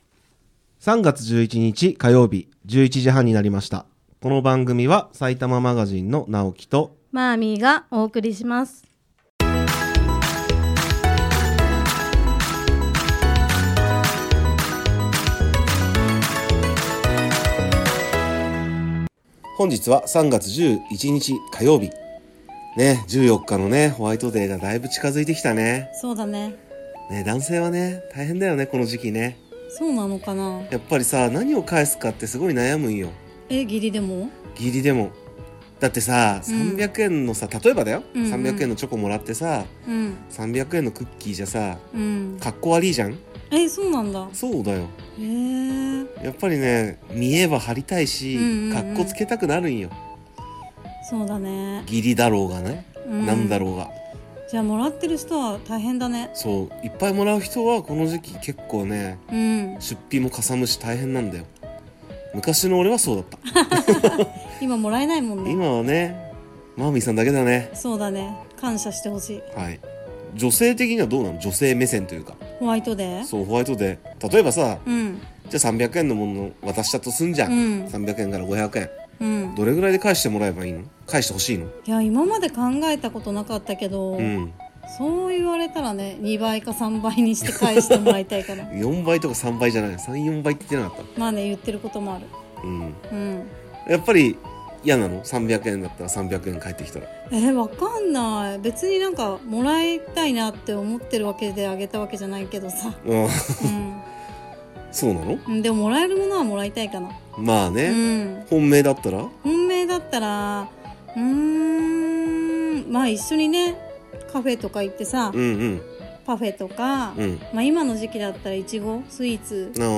4つの市町村の方がゲスト出演する回でした！！皆さんもスイーツを食べて写真を撮り、景品をゲットしましょう。